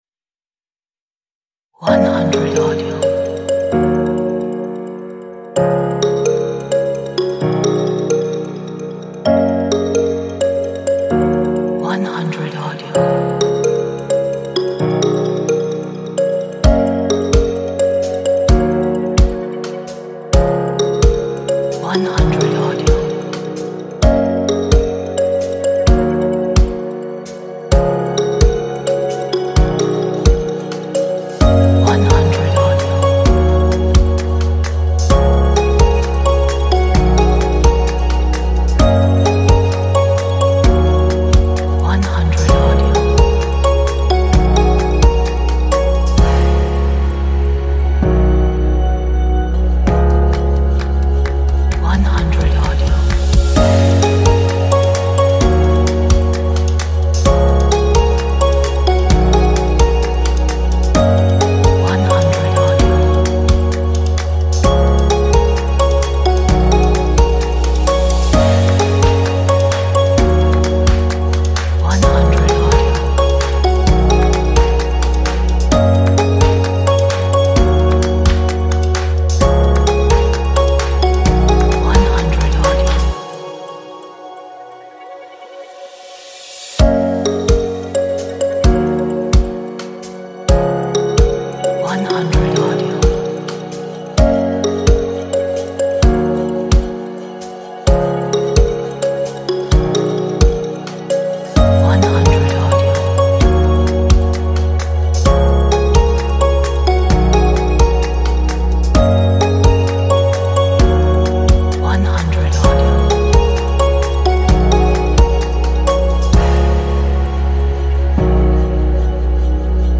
这是一首心情乐观的音乐。